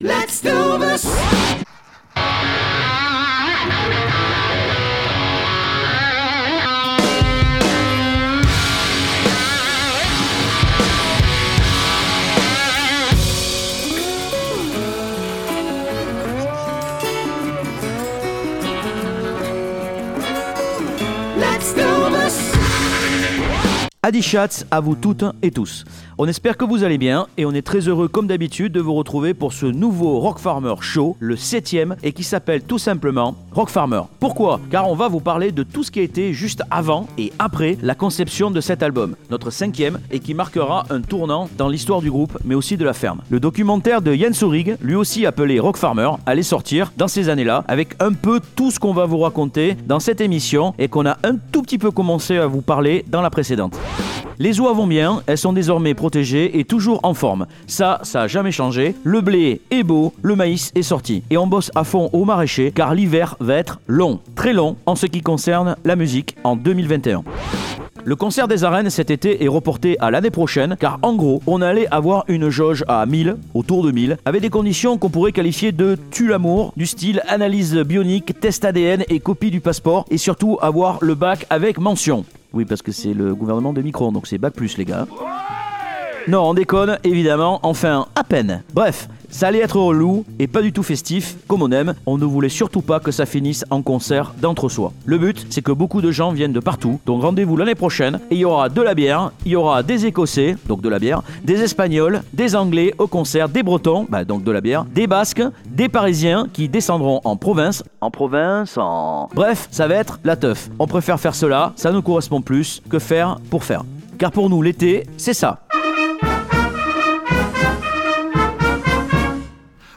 El programa de radio de la música orgánica.